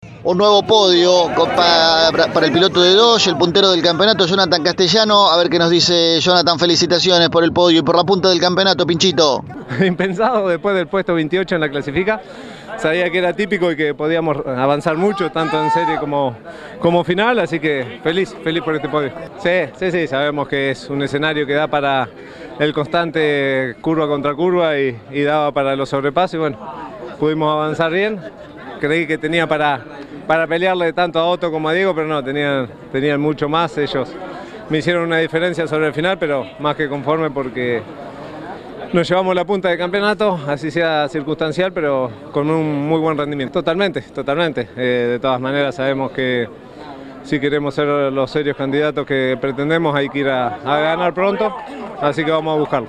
El piloto de Lobería pasó por los micrófonos de Pole Position y habló de un nuevo podio obtenido está temporada, que lo posiciona como nuevo líder del campeonato del Turismo Carretera.